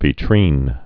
(vē-trēn)